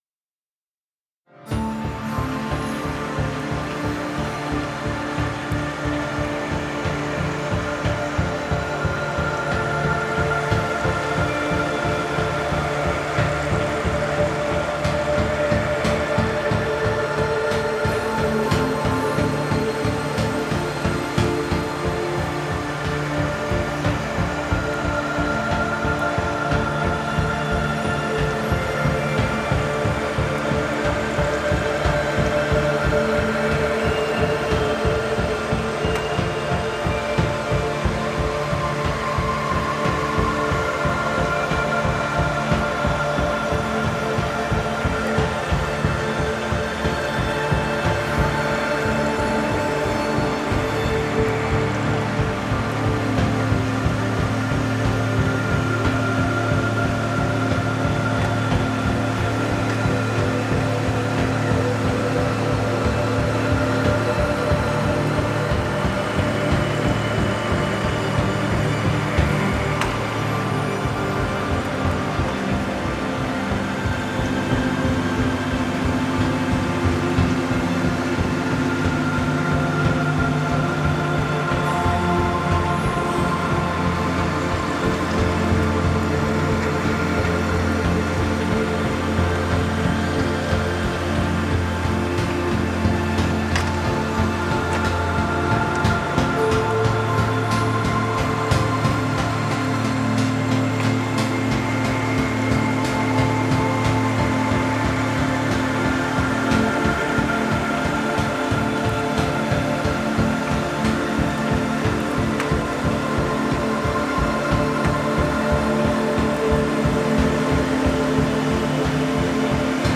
-小雨，中雨和大雨有多种变化
-夜间城市雨有多种变化
-远处的雨和风具有多种变化
单个补丁可以使小雨慢慢转变成咆哮的风暴。